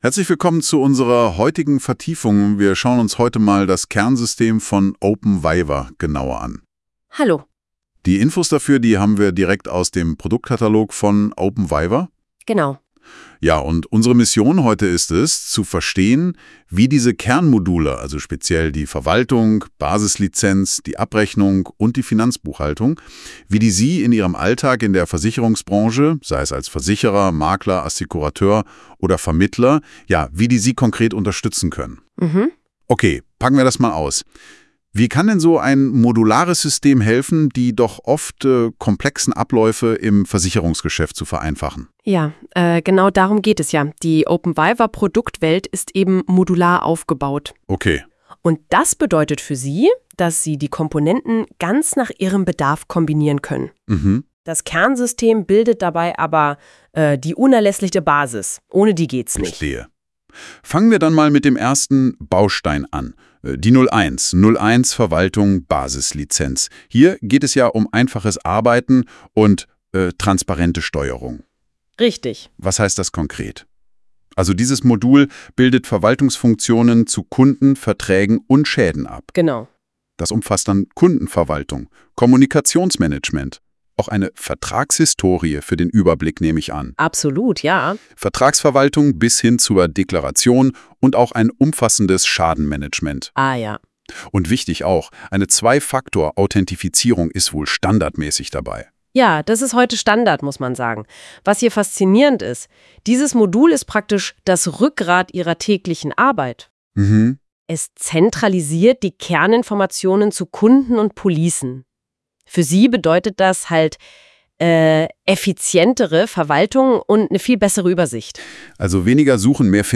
Praxisnah und verständlich erklärt uns die künstliche Intelligenz von Google NotebookLM die Modulgruppen von openVIVA c2.